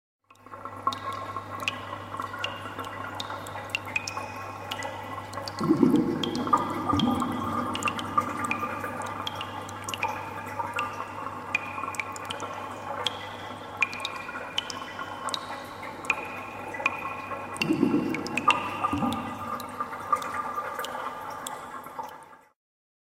Sewer Sound Effect
Category: Sound FX   Right: Personal